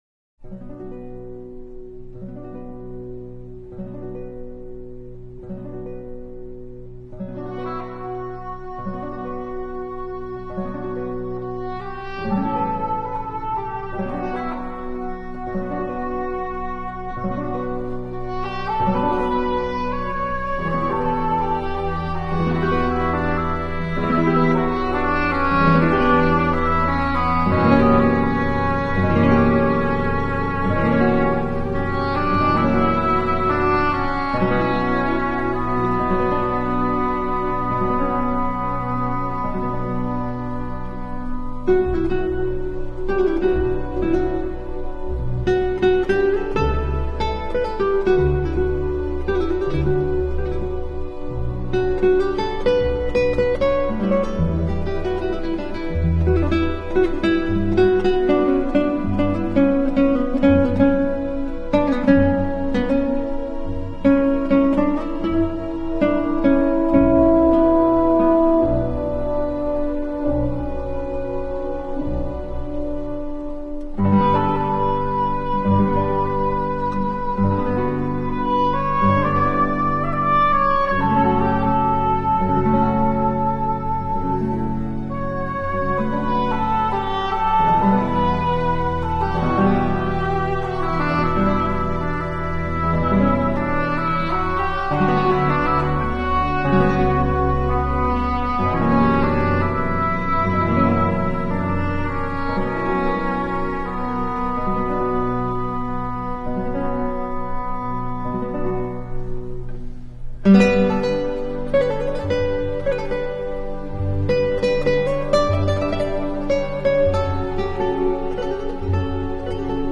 Easy listening for the end of the day.